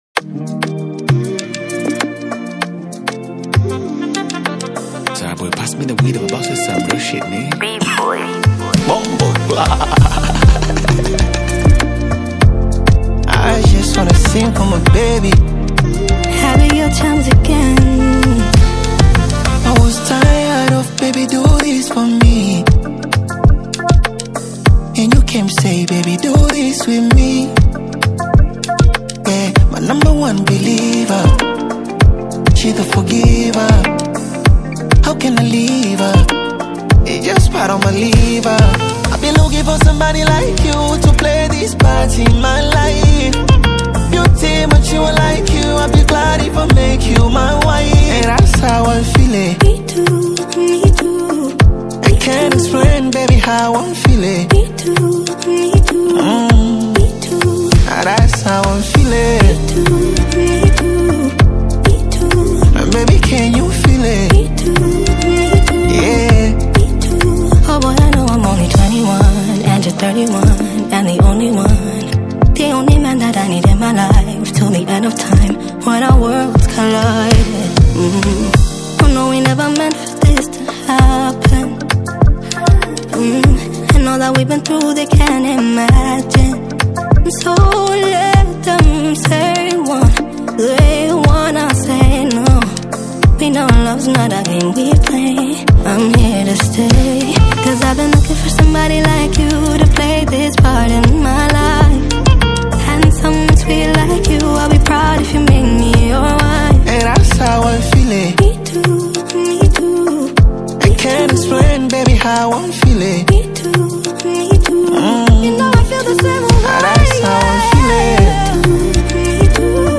Popular Tanzanian urban music